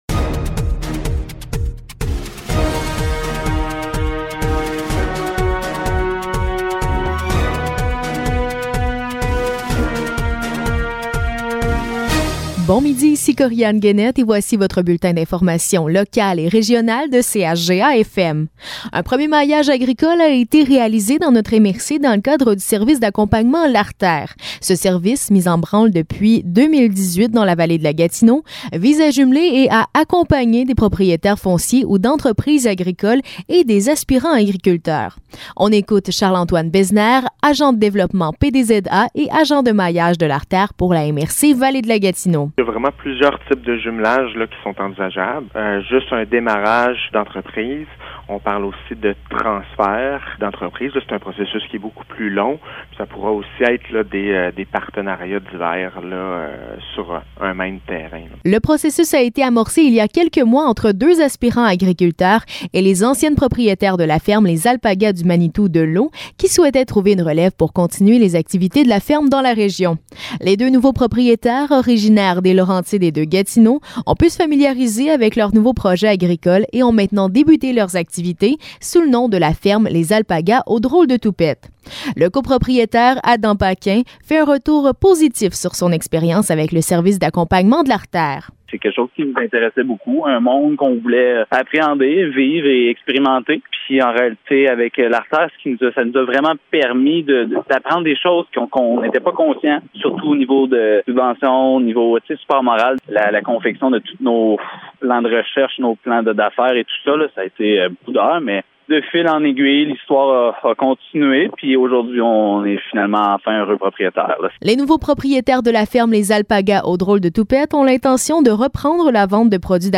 Nouvelles locales - 1er octobre 2020 - 12 h